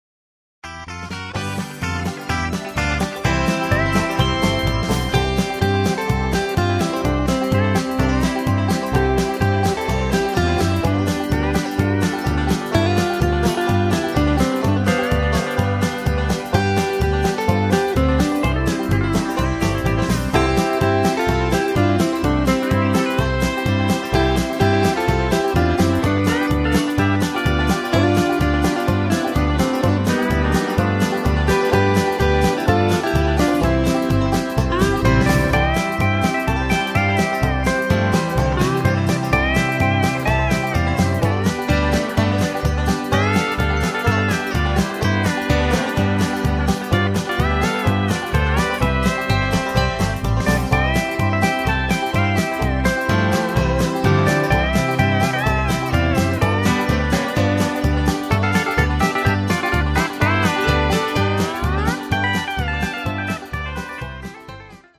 (Instrumental)
Singing Calls